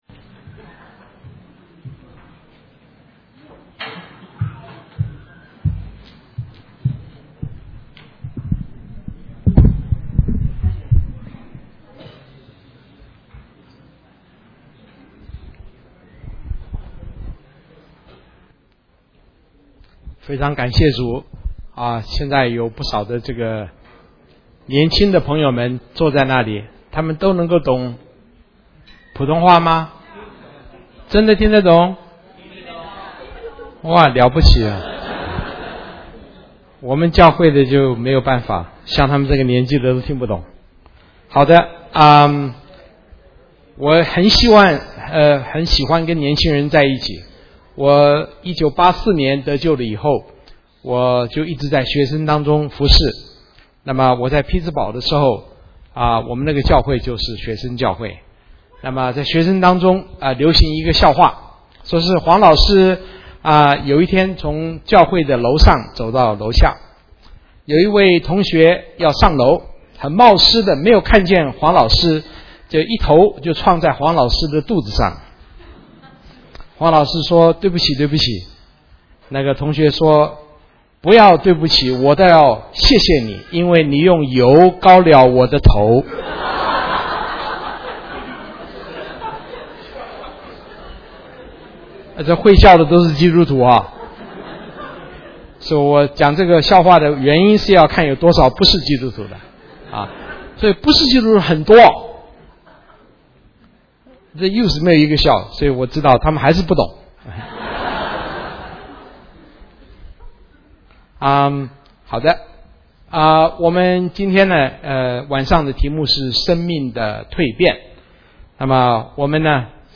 Mandarin Sermons